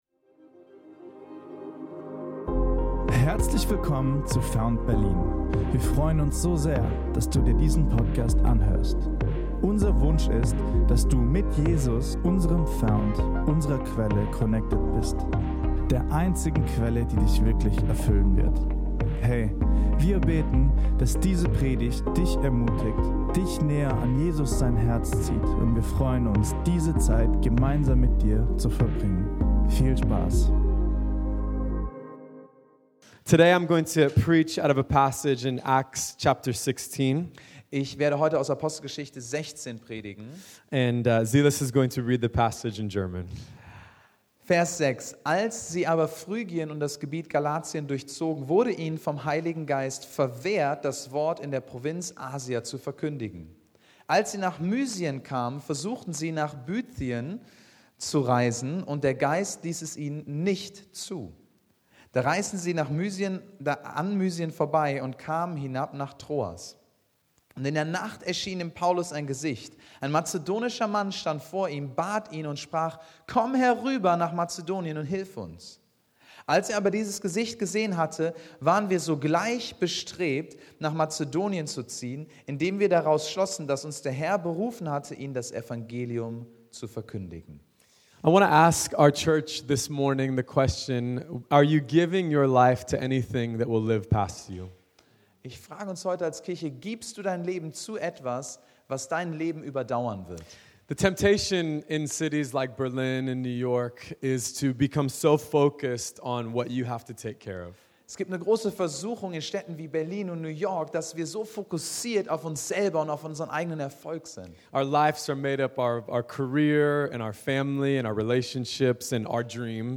08-10_Sunday_Service_2nd.mp3